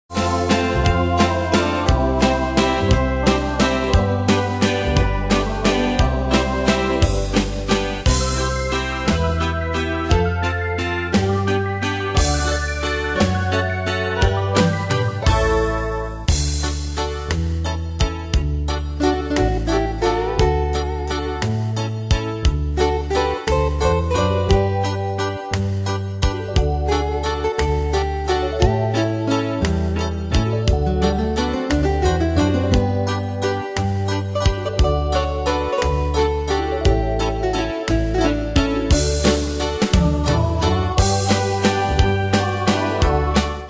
Style: Country Ane/Year: 1992 Tempo: 175 Durée/Time: 3.14
Danse/Dance: Valse/Waltz Cat Id.
Pro Backing Tracks